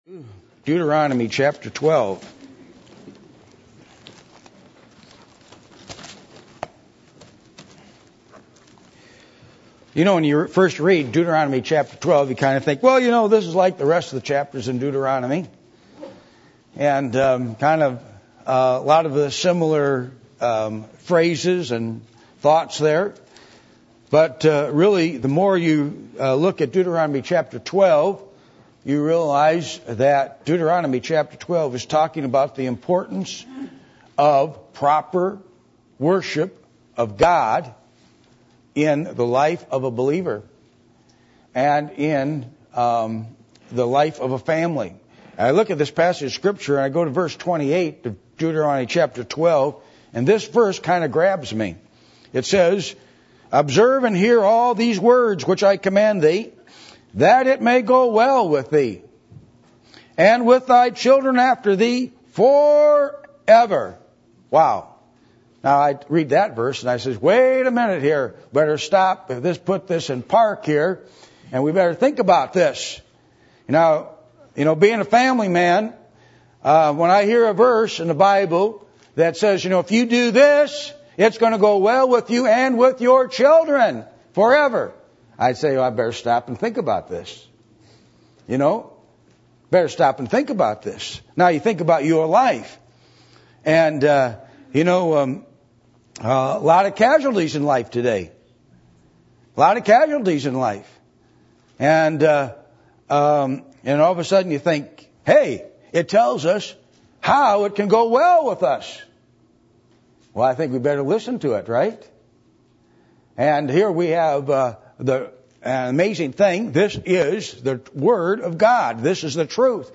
Passage: Deuteronomy 5:6-15 Service Type: Sunday Morning %todo_render% « Who Do You Identify With?